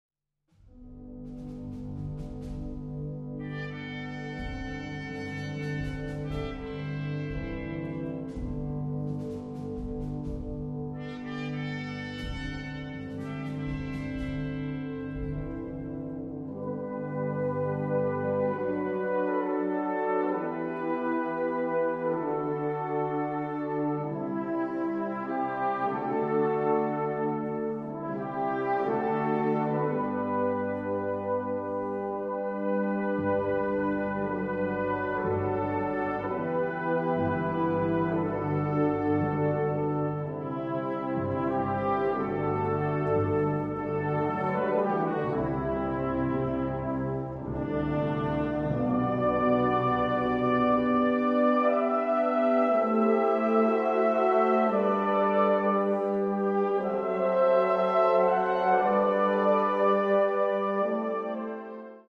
Prachtige gevoelvolle muziek